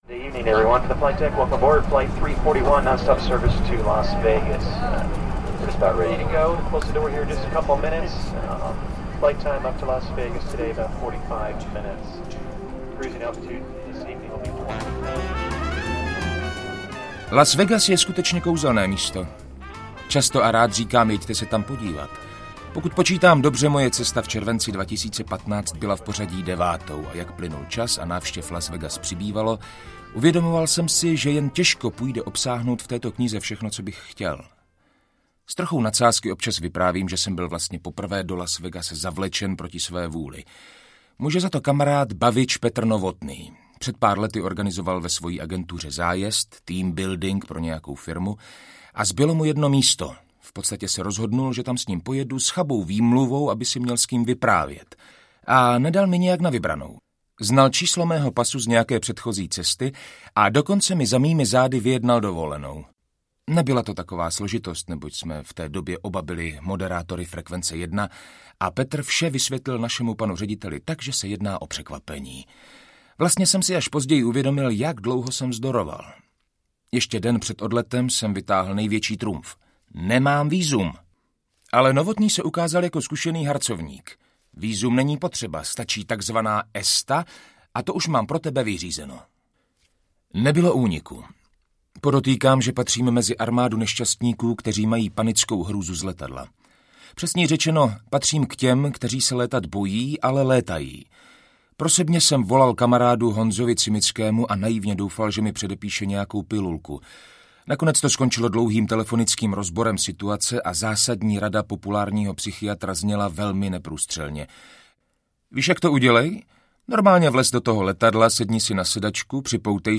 Moje Las Vegas audiokniha
Ukázka z knihy